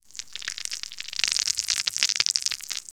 The sound of slime dying
the-sound-of-slime-dying-l2eiklfs.wav